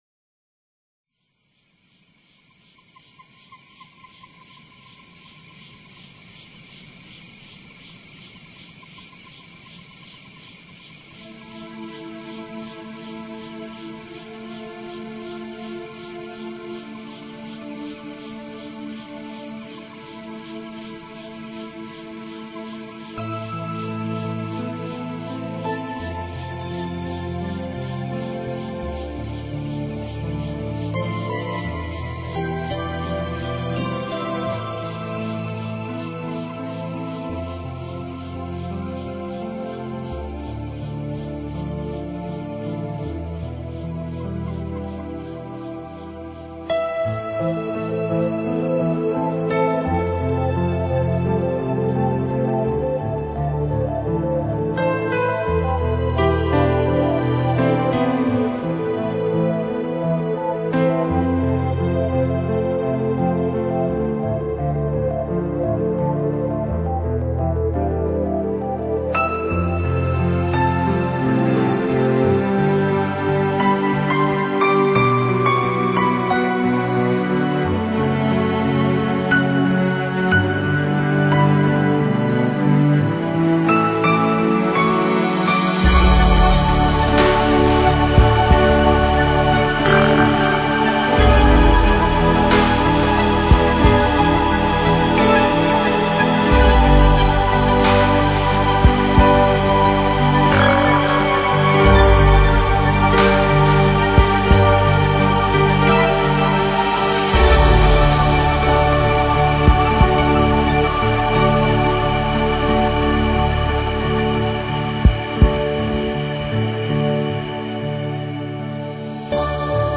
活泼的旋律和愉快的和声贯穿在那闪闪发光的声音波浪之中，把听众带进 一个轻盈和谐的世界里。聆听时刻，神秘瞬间交互出现。
风格: Newage